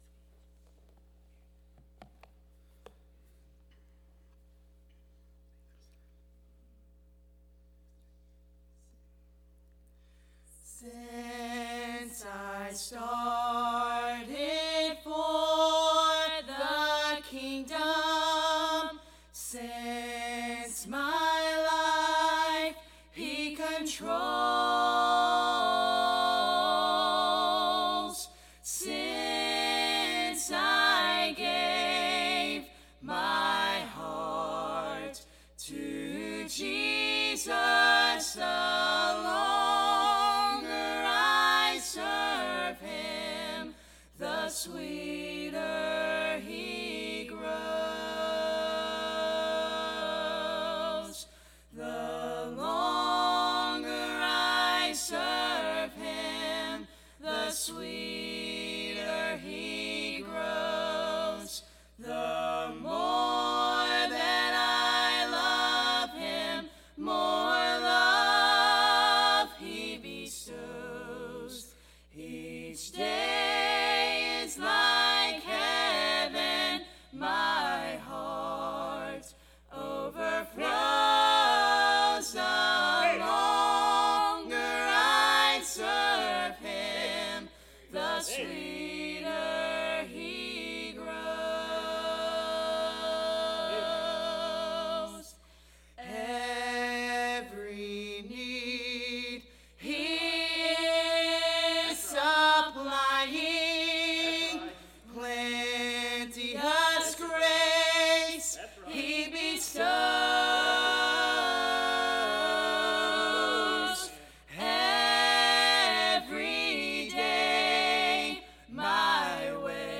Sermons Archive • Page 38 of 166 • Fellowship Baptist Church - Madison, Virginia